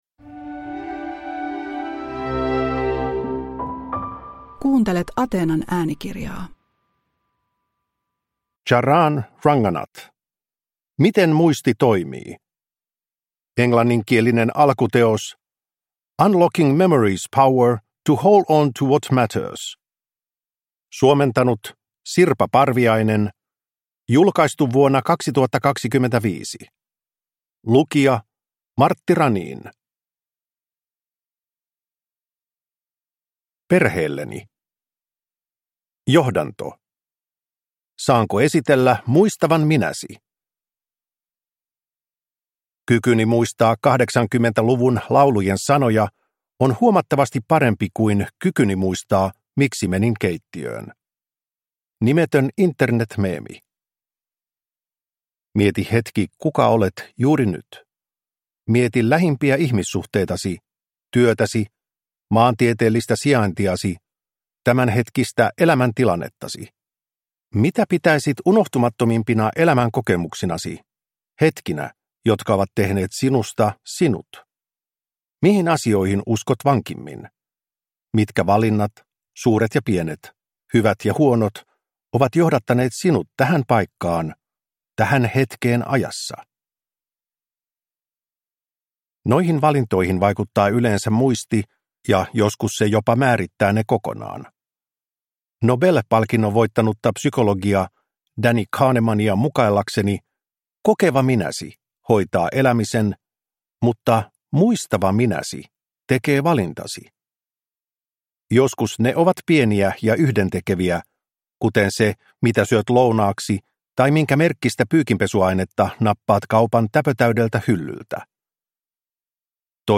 Miten muisti toimii – Ljudbok